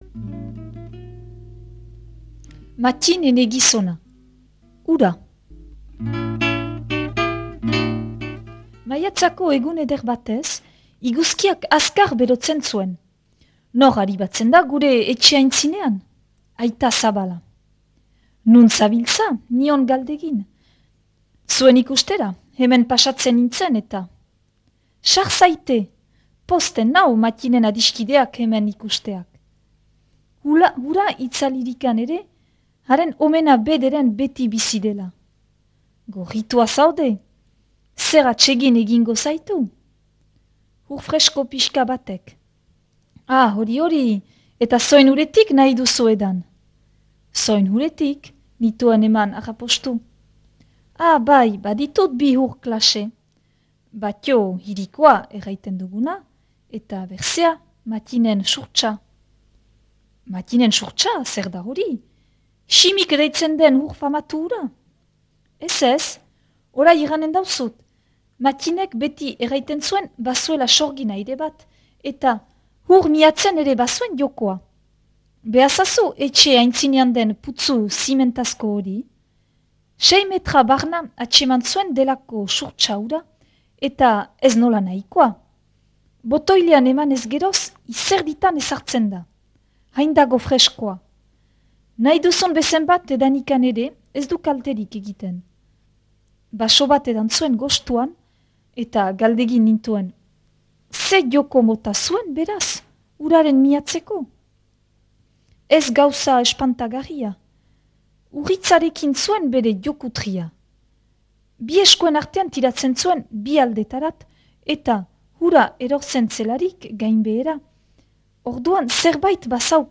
irakurketa